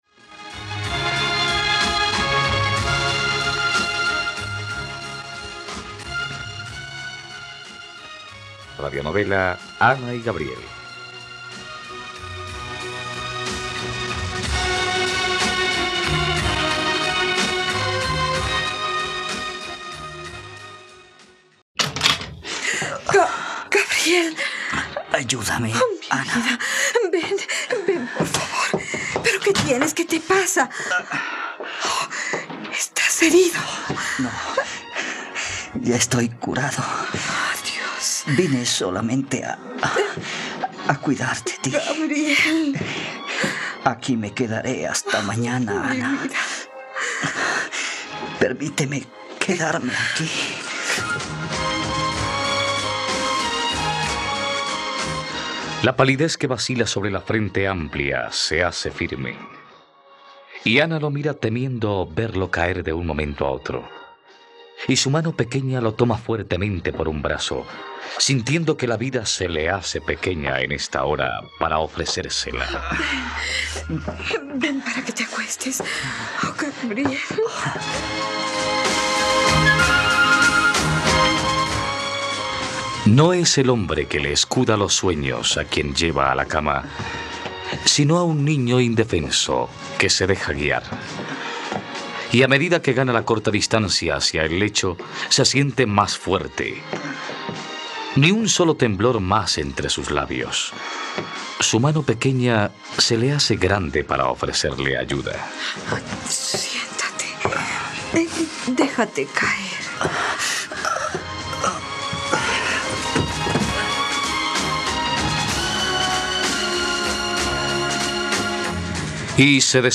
Ana y Gabriel - Radionovela, capítulo 61 | RTVCPlay